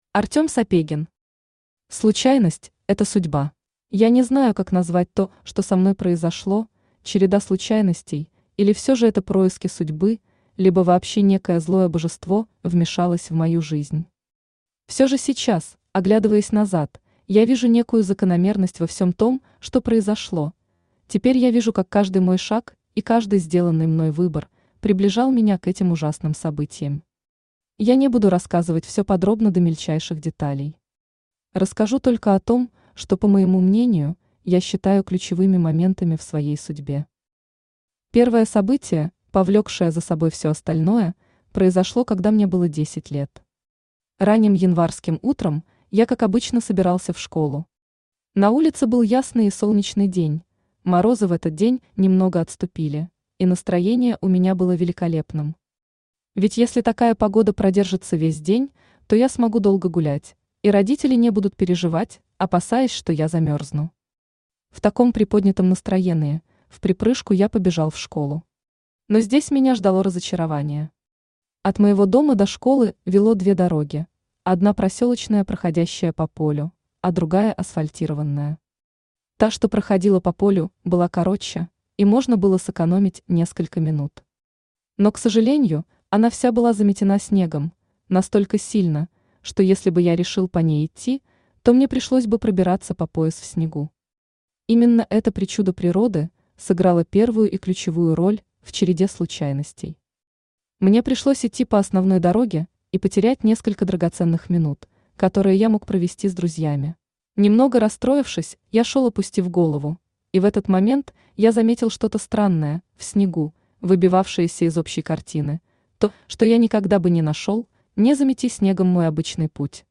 Аудиокнига Случайность это – судьба | Библиотека аудиокниг
Aудиокнига Случайность это – судьба Автор Артем Михайлович Сапегин Читает аудиокнигу Авточтец ЛитРес.